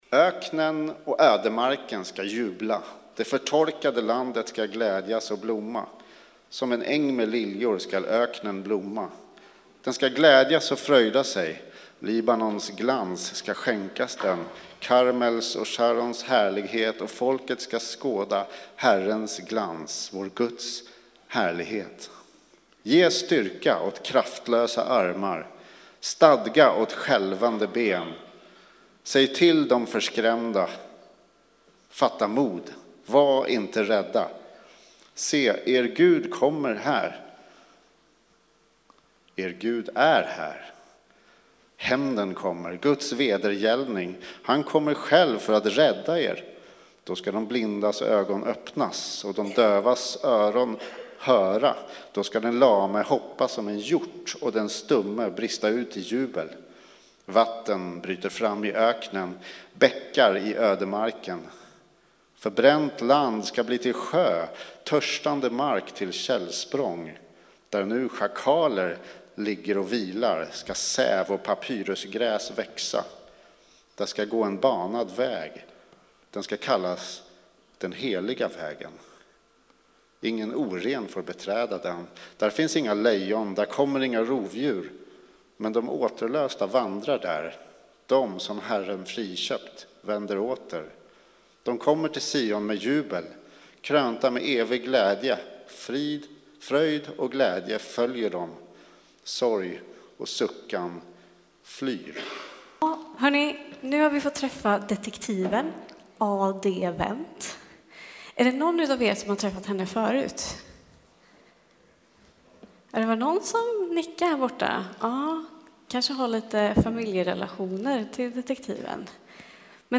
Textläsning och predikan från Gudstjänst med alla åldrar.